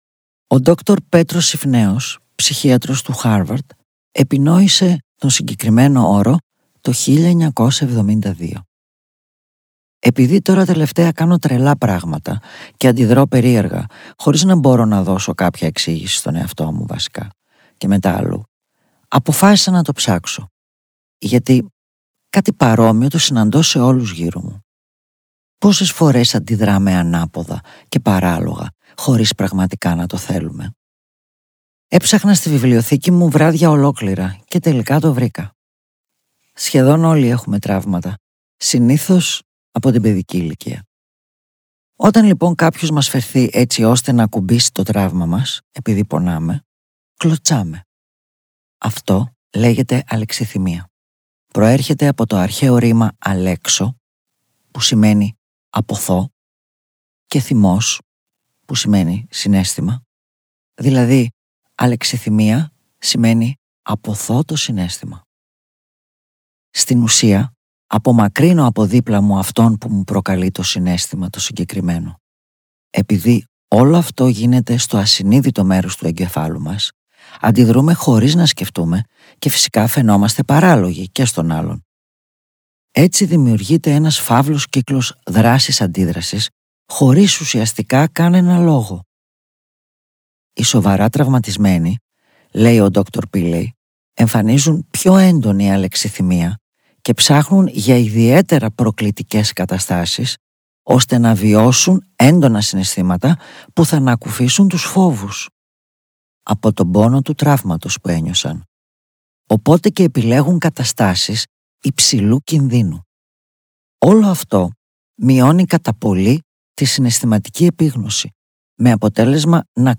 “Η τέχνη της χαράς”. Το νέο audiobook της Κατερίνας Γιατζόγλου μόλις κυκλοφόρησε από την JukeBooks.
Με αφήγηση από την ίδια την Κατερίνα Γιατζόγλου.